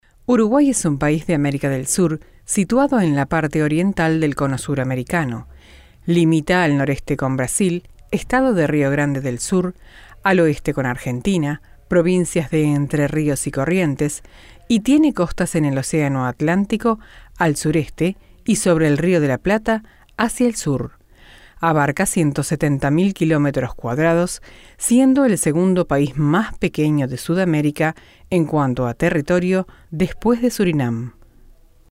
voz melodiosa y suave,transmite confianza y tranquilidad.
kastilisch
Sprechprobe: Industrie (Muttersprache):
pleasant and melodious voice, trnsmite peace and security.